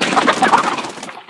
PixelPerfectionCE/assets/minecraft/sounds/mob/chicken/hurt1.ogg at bbd1d0b0bb63cc90fbf0aa243f1a45be154b59b4
hurt1.ogg